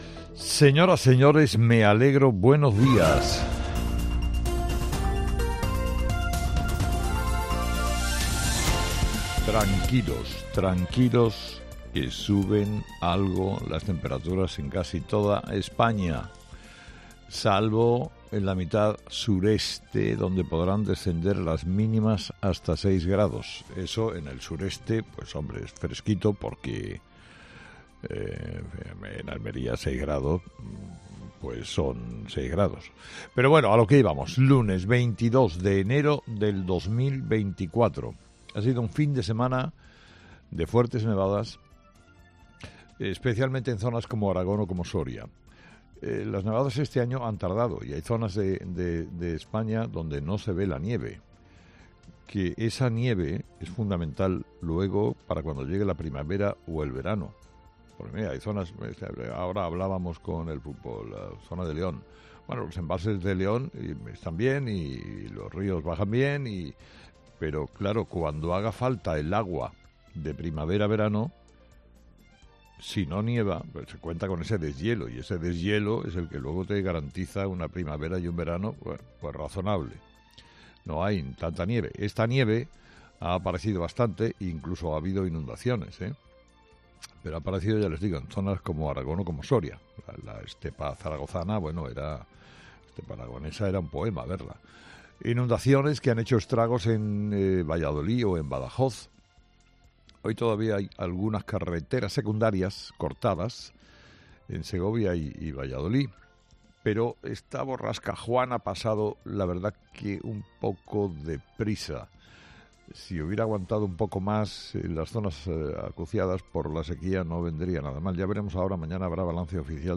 Escucha el análisis de Carlos Herrera a las 06:00 en Herrera en COPE del lunes 22 de enero